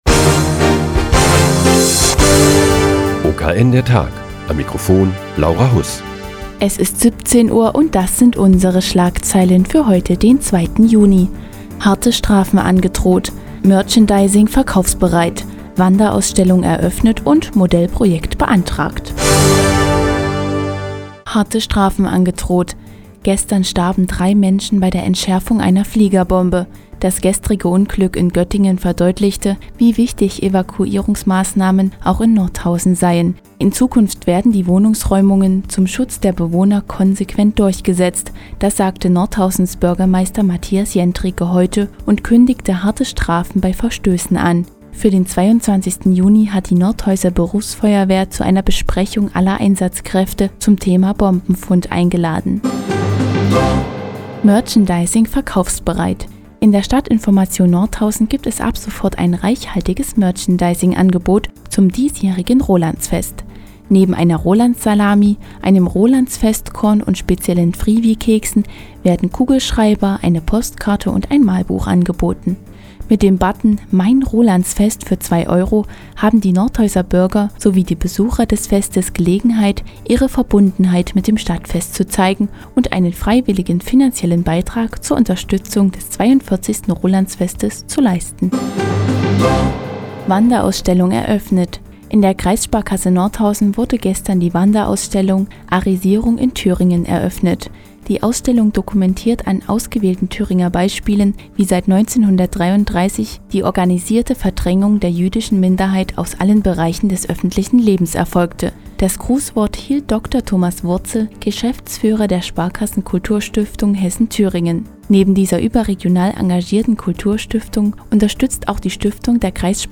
Die tägliche Nachrichtensendung des OKN ist nun auch in der nnz zu hören. Heute geht es um die Fanartikel für das diesjährige Rolandsfest und die neue Wanderausstellung in der Kreissparkasse Nordhausen.